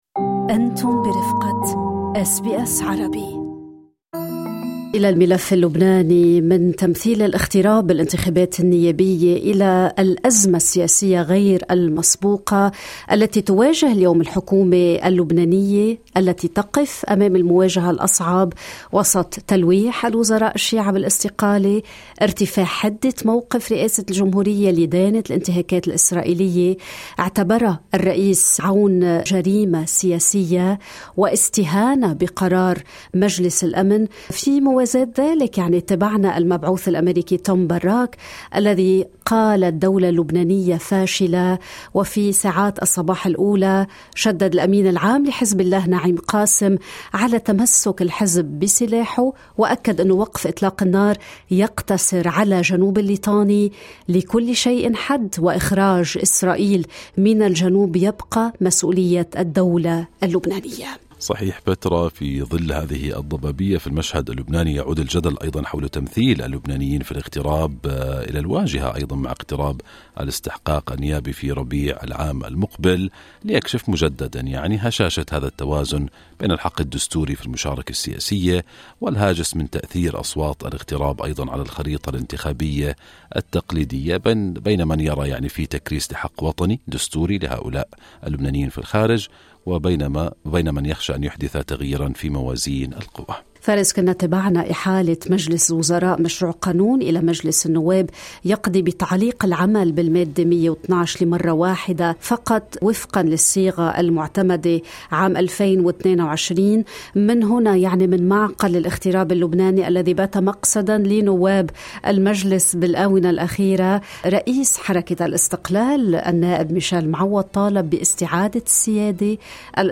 يقف ابن الرئيس الأول بعد الطائف على عتبة الذكرى ال٣٦ لاغتيال والده الرئيس رينيه معوض، ومن سيدني يفصّل النائب ميشال معوّض رؤيته للخروج من الأزمة الأمنية والسياسية التي يعيشها لبنان، معتبرًا أنّ لبنان الدولة المخطوفة منذ 1969 أمام فرصة تاريخية وأن ولا خلاص إلا بحصرية السلاح وباستعادة القرار للدولة. هو الذي دعا الاغتراب الى التسجيل لانتزاع حقهم المسلوب بالتمثيل الأنسب، أكّد ان الرئيس برّي يختطف مجلس النواب من الأكثرية.